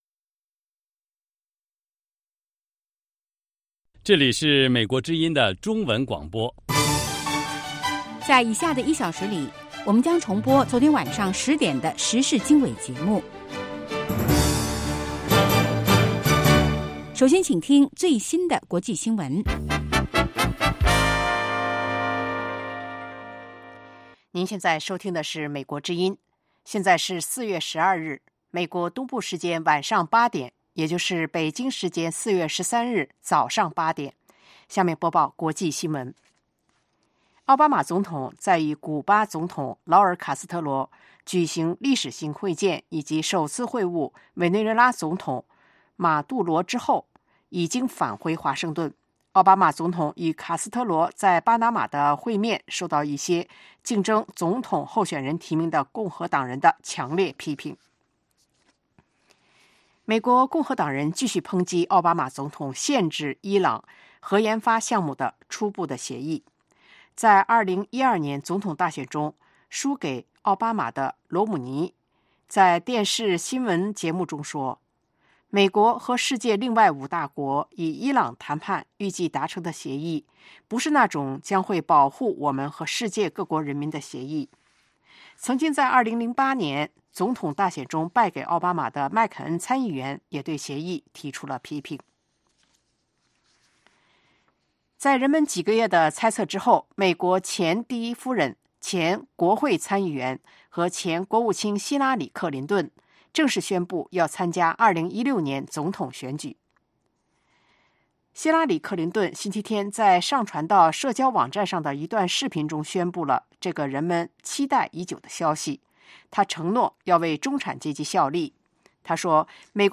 北京时间早上8-9点广播节目 这个小时我们播报最新国际新闻，并重播前一天晚上10-11点的时事经纬节目。